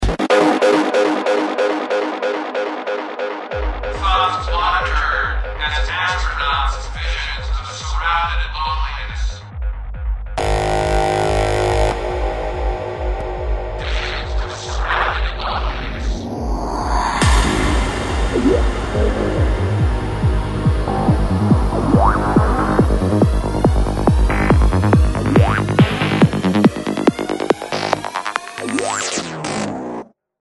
Треск при записи